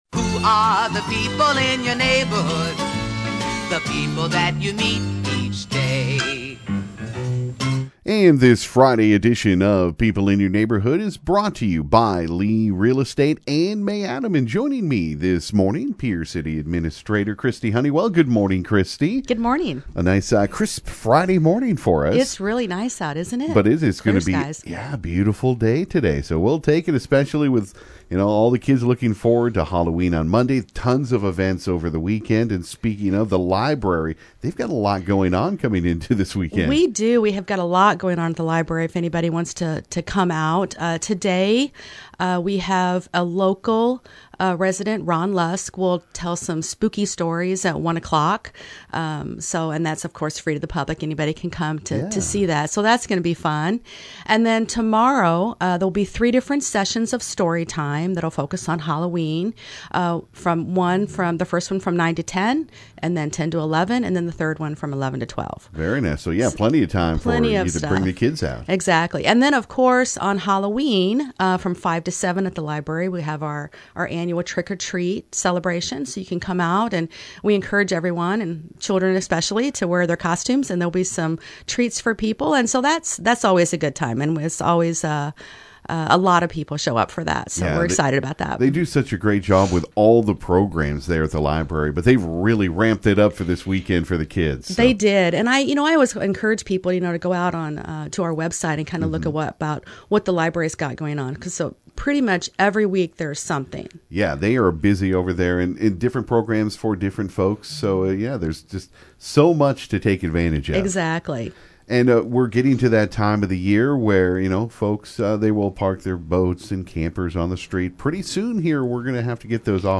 This morning Pierre City Administrator Kristi Honeywell stopped by the KGFX studio to give an update about what’s going on in the city.